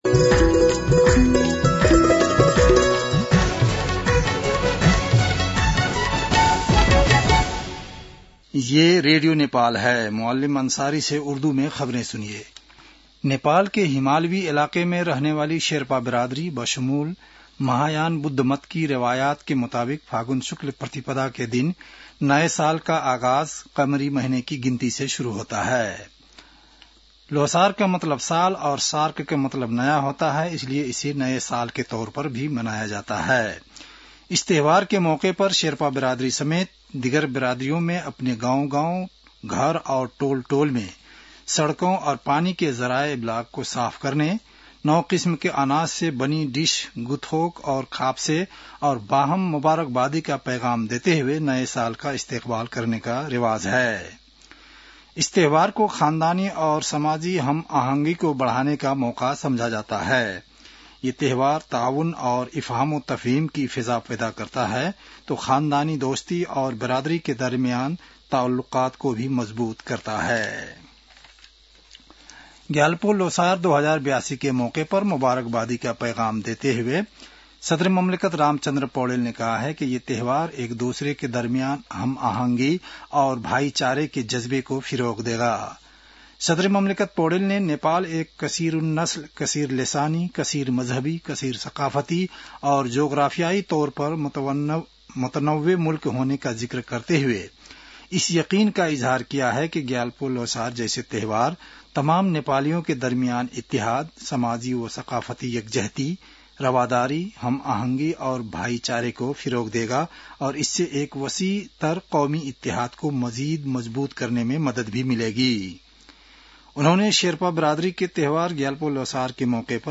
उर्दु भाषामा समाचार : ६ फागुन , २०८२